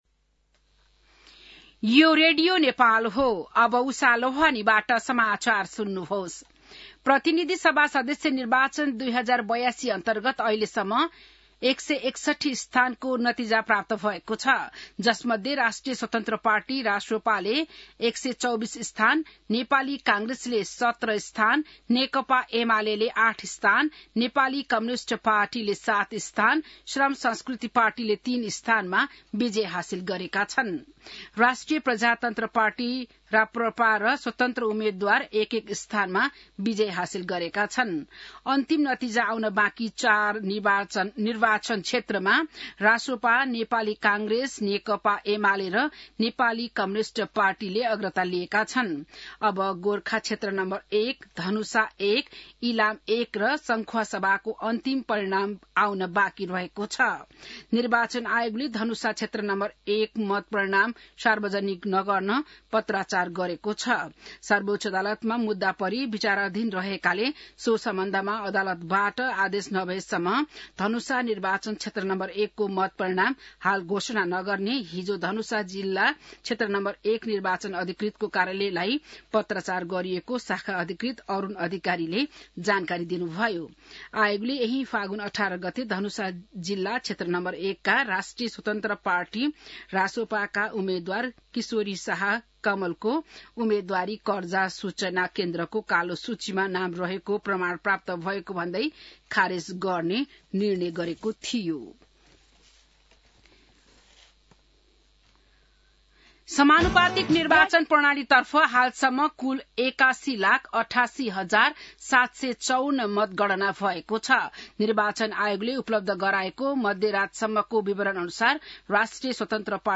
An online outlet of Nepal's national radio broadcaster
बिहान १० बजेको नेपाली समाचार : २५ फागुन , २०८२